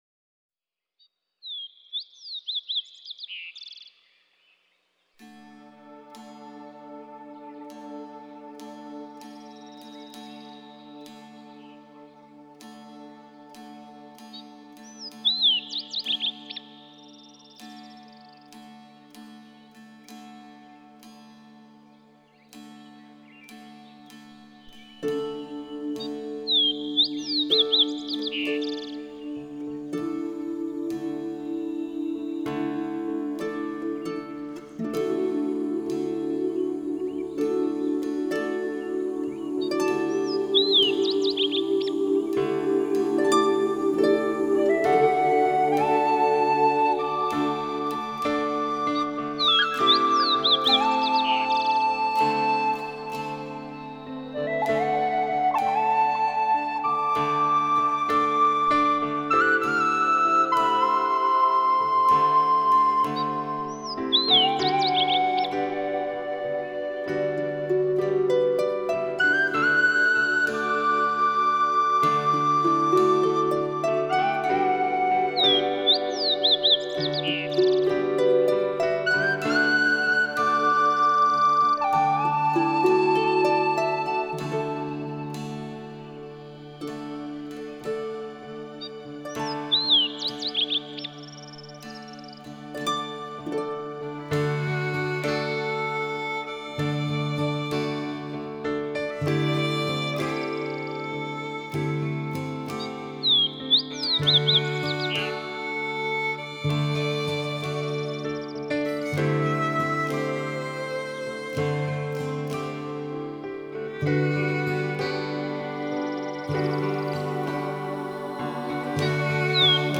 冥想类音乐1
冥想音乐能帮助我们放松身心，还能减轻压力，集中注意力......